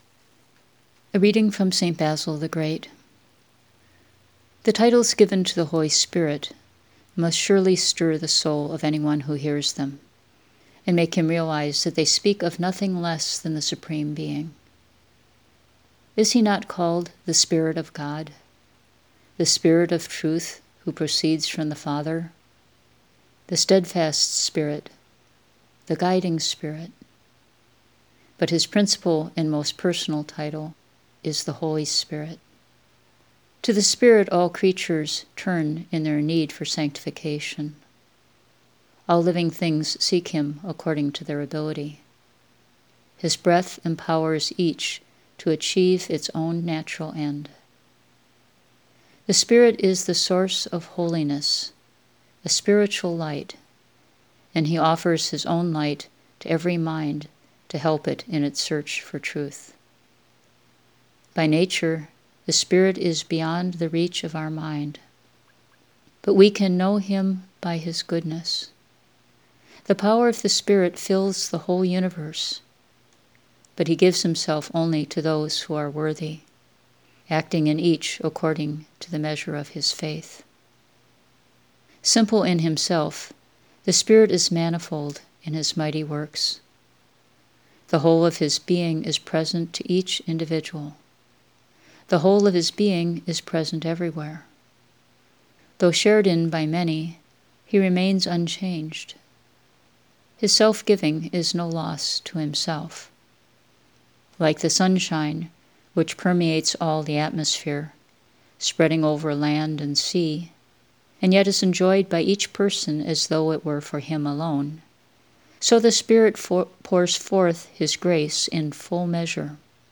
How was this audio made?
I recorded both readings while at the hermitage (using an app on my cell phone, one of my few concessions to technology) so that I could share the experience with you.